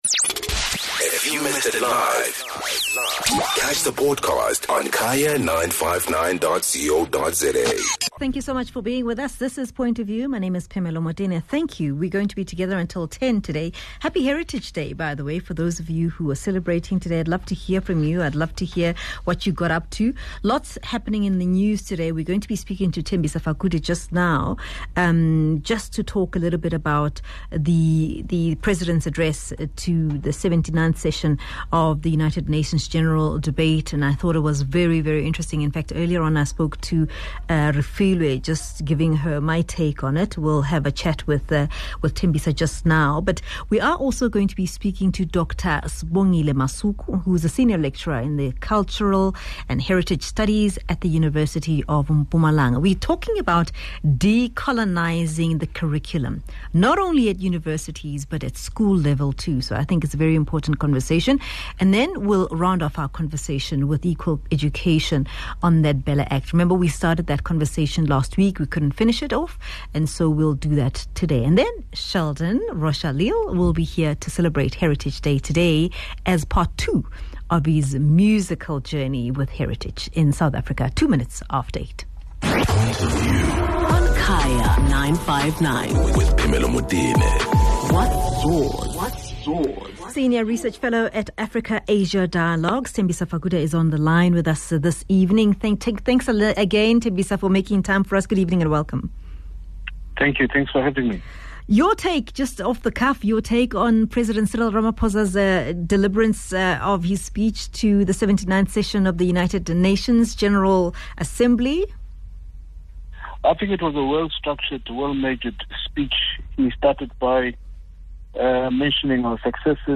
24 Sep Ramaphosa addresses 79th Session of the UN General Assembly